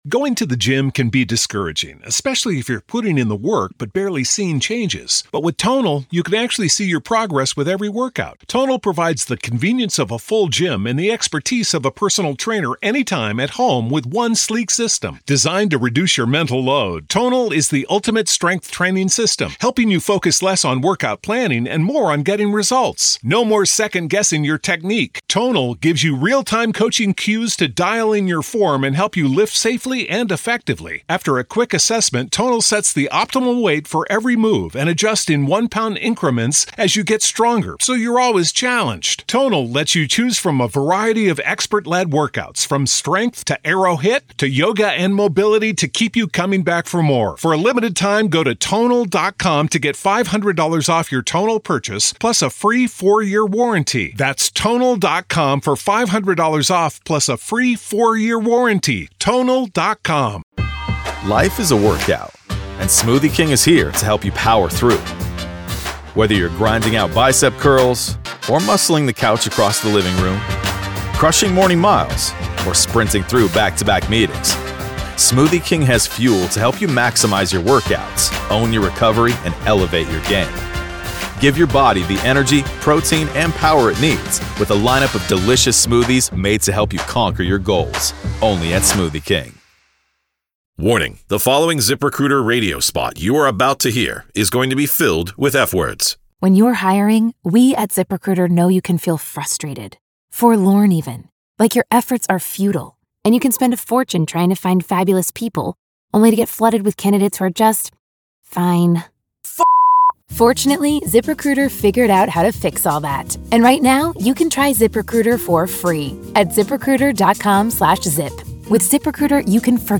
The conversation sheds light on the complexities of the case, highlighting several key points: